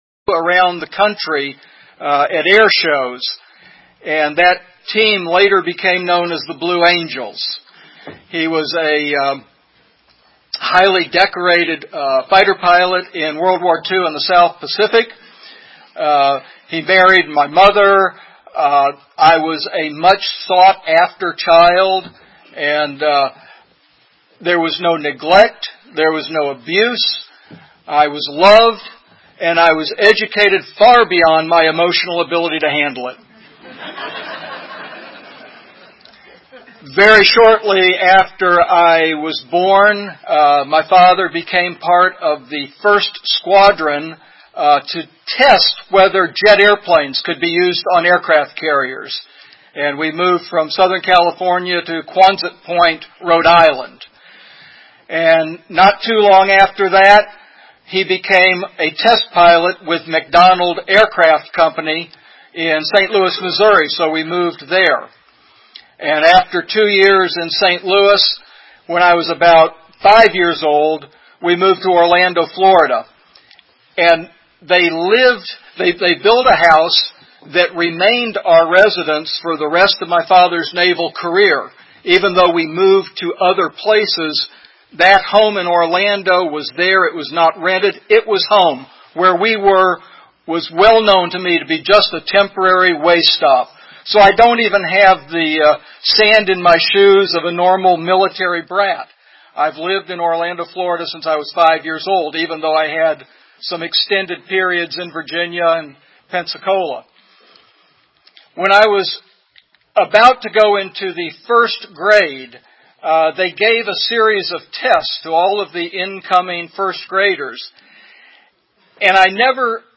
EVENT: 20th Unity Weekend TYPE: AA, Male, Story
Funny and entertaining.